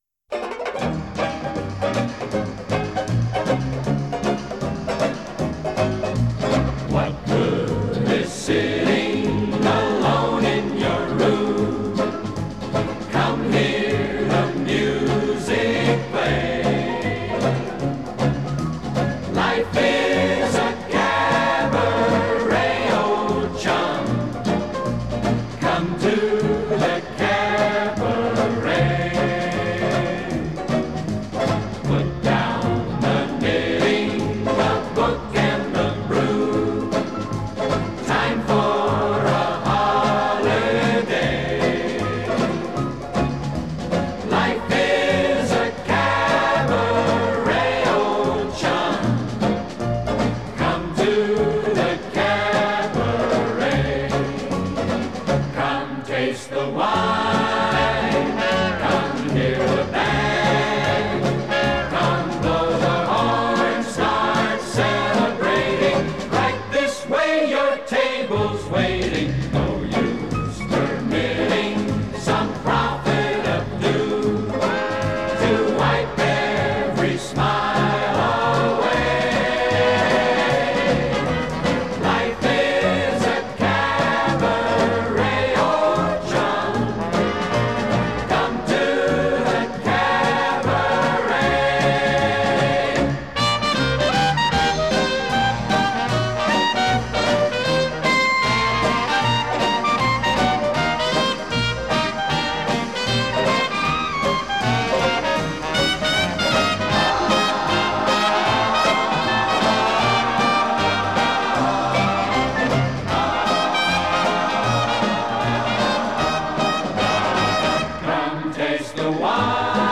Жанр: Orchestral Jazz / Easy Listening